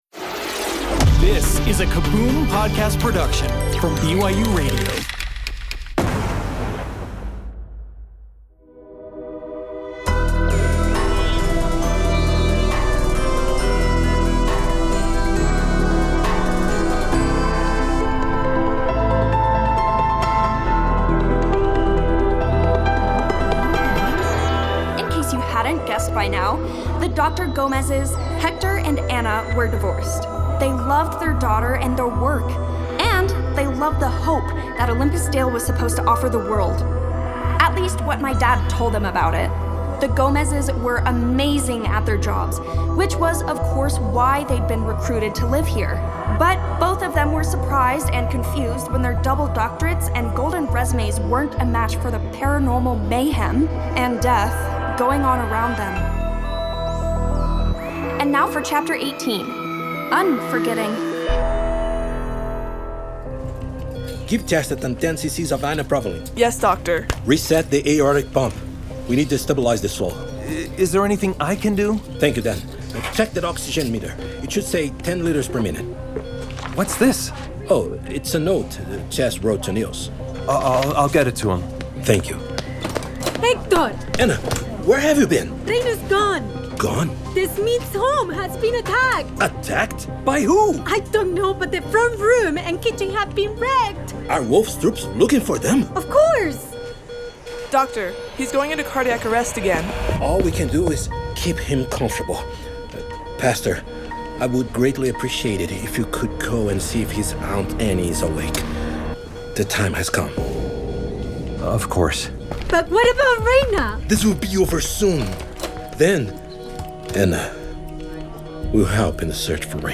… continue reading 22 episodes # Bedtime Stories # Audio Drama # BYUradio # Sci-Fi / Fantasy Stories